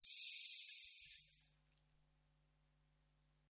Siseo de serpiente
Sonidos: Animales Reptiles